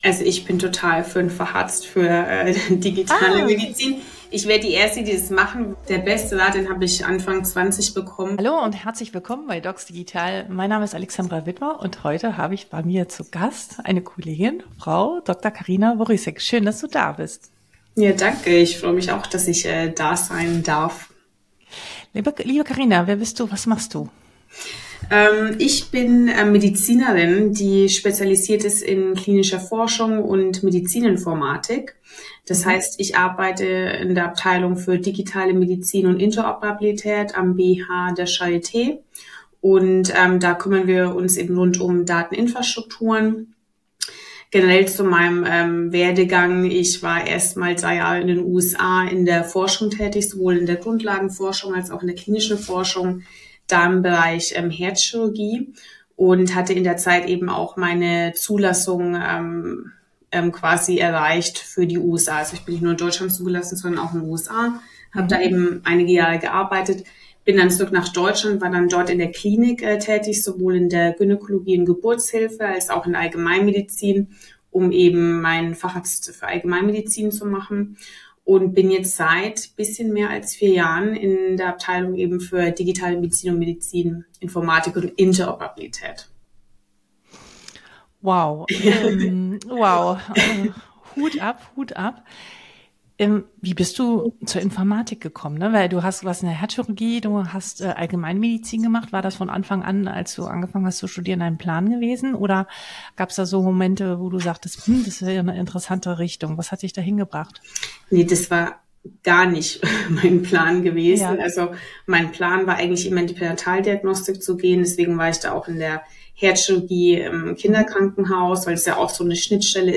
Chancen, Risiken und ärztliche Verantwortung Ein inspirierendes Gespräch für alle Ärzt:innen, Tech-Interessierte und Entscheider:innen im Gesundheitswesen, die Medizin neu denken wollen.